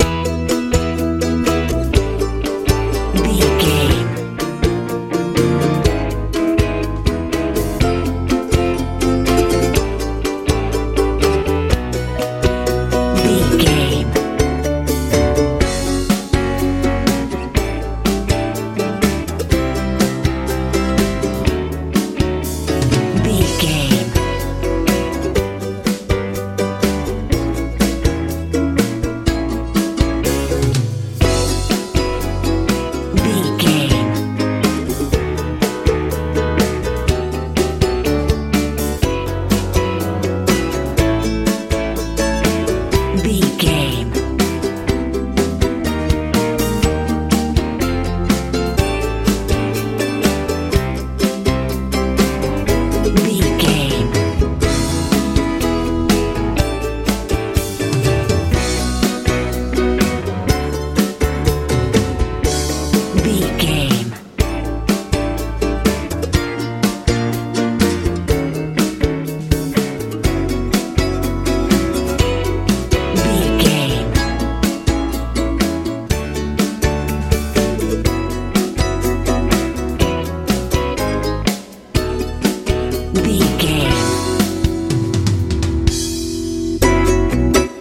lite pop feel
Ionian/Major
hopeful
electric guitar
piano
bass guitar
drums